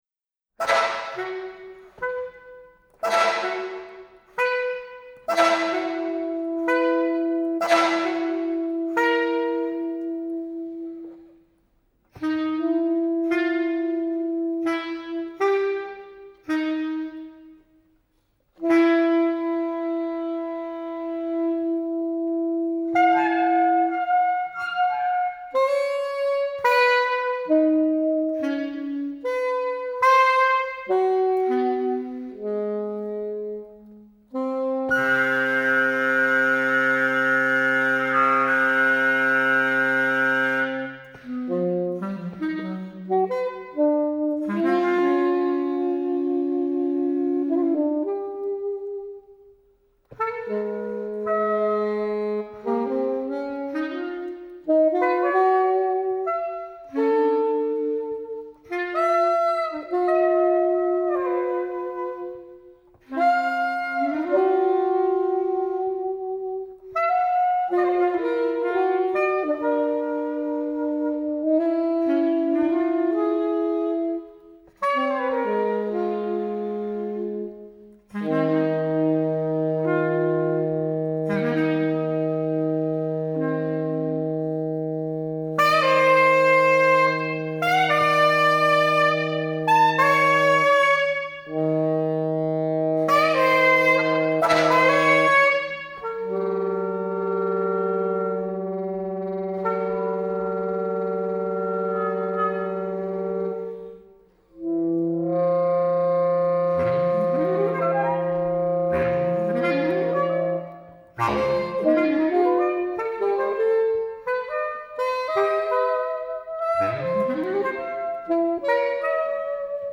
bass clarinet
c-melody-sax live at Hfmdd